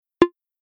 Macでは、入力ミスや操作ミスの時に流れる通知音があります。
このような「ポンッ」というサウンドが流れます。